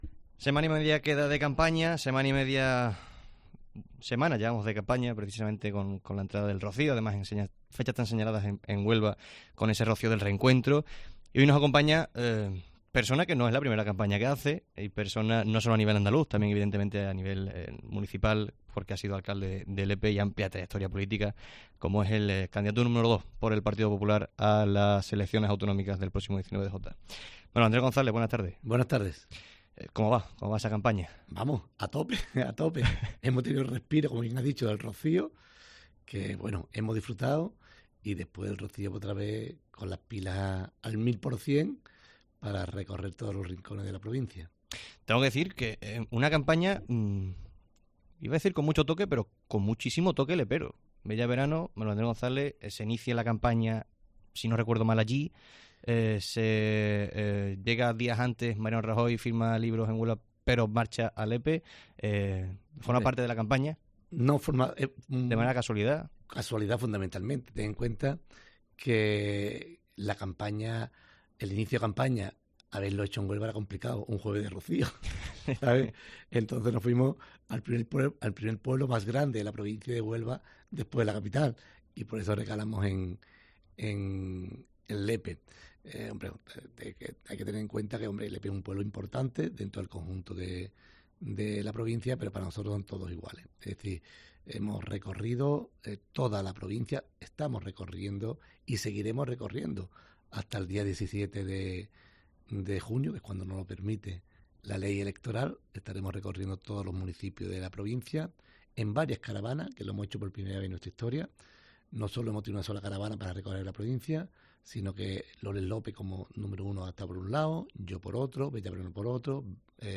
El presidente del PP de Huelva, Manuel Andrés González, hace análisis de campaña en los micrófonos de COPE Huelva semana y media antes de las...